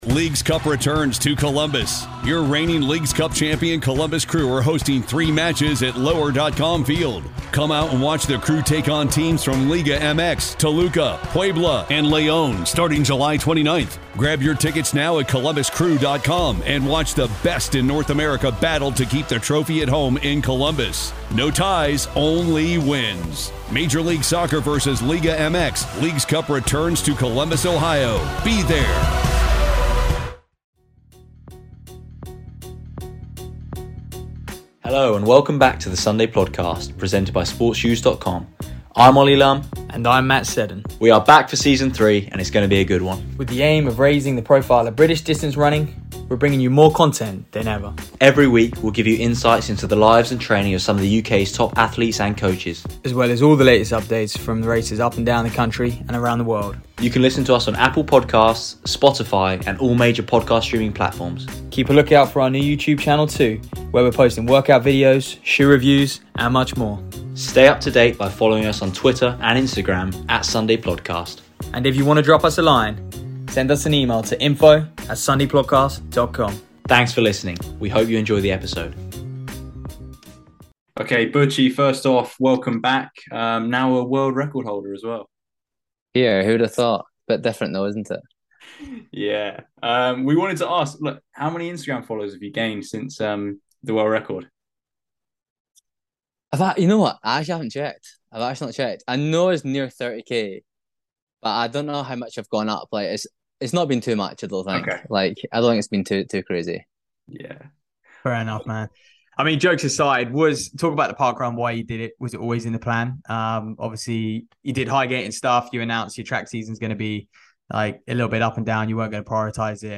Butchy ran the time of 13:45 at Edinburgh parkrun on Saturday, taking down Andy Baddeley's previous record of 13:48 from 2012. We spoke to Butchy about the run itself, his plans for the rest of the season, and much more.